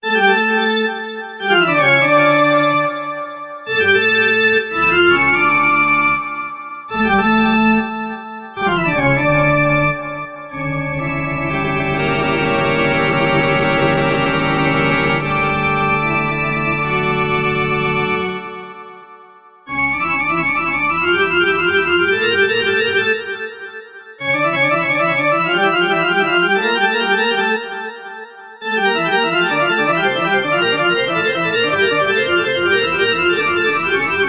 ヨーロッパの音楽史の中で最も有名なオルガン作品。
「チャララ～チャラララランラン」で始まる曲を一度は聞いたことがあるはず。